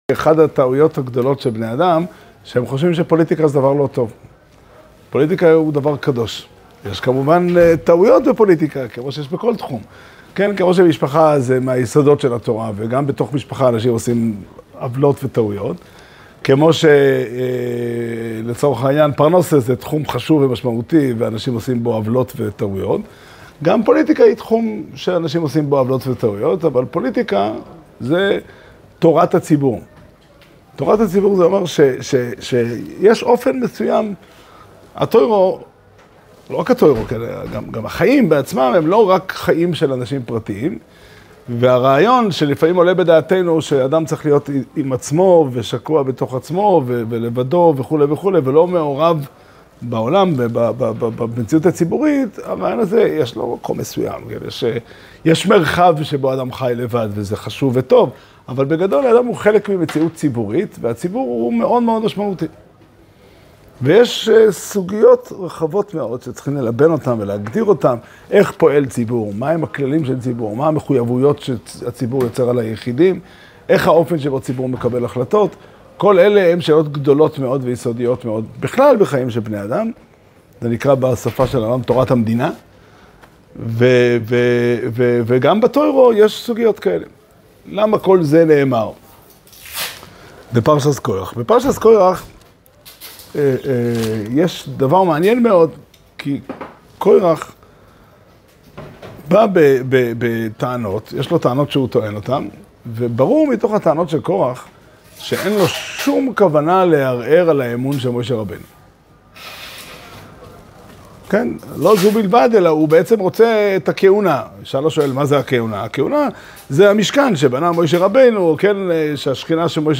שיעור שנמסר בבית המדרש פתחי עולם בתאריך כ"ה סיון תשפ"ד